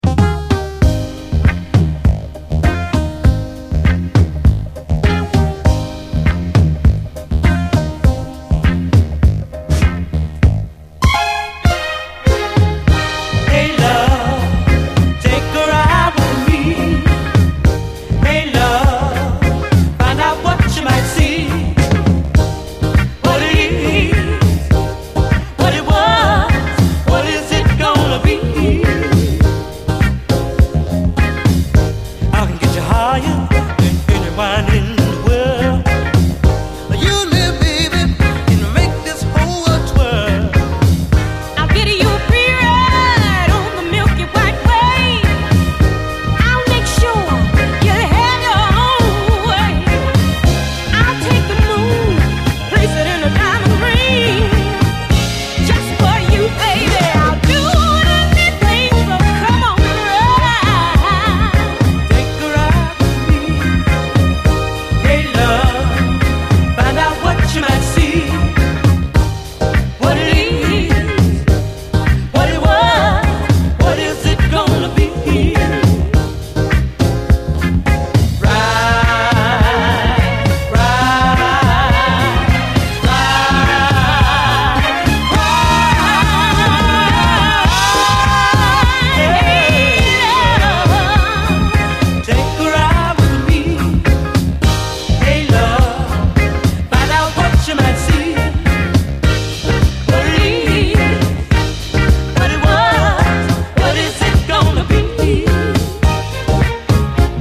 SOUL, 70's～ SOUL
デトロイトの男女デュオによるラヴリー・ミディアム・ソウル！
柔らかなサウンドが包み込むラヴリー・ミディアム・ソウル！